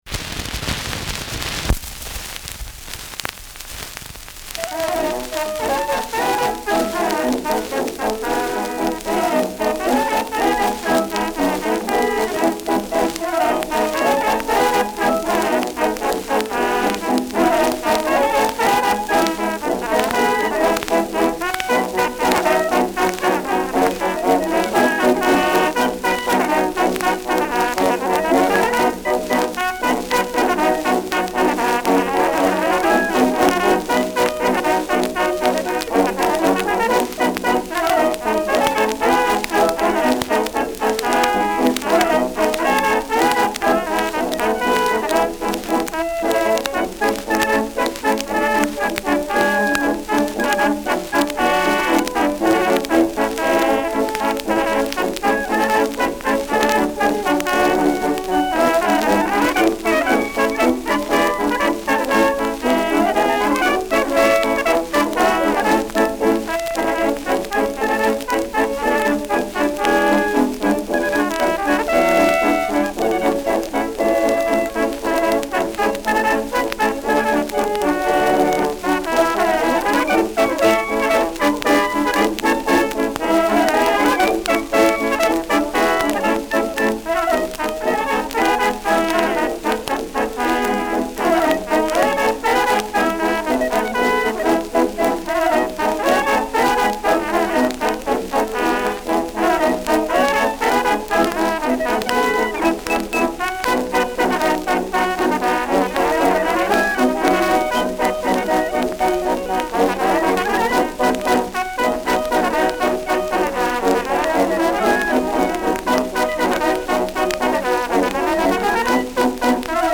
Schellackplatte
präsentes Rauschen
[Ansbach] (Aufnahmeort)